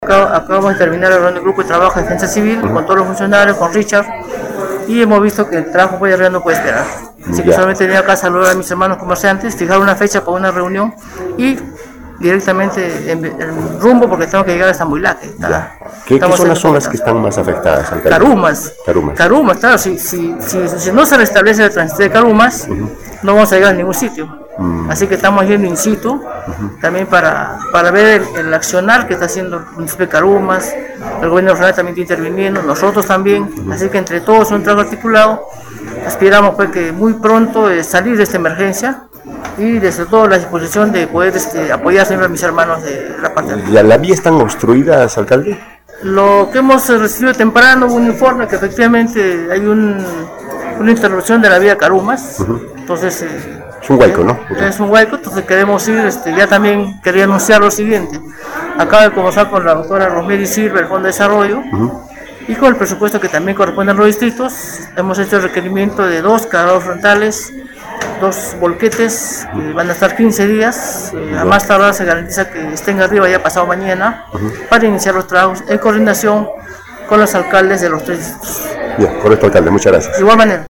Larry Cohaila, alcalde provincial de Mariscal Nieto, dijo a Radio Uno Moquegua, fue acordado en reunión de Defensa Civil, accionar el traslado de ayuda a la zona afectada.
ALCALDE-MARISCAL-NIETO.mp3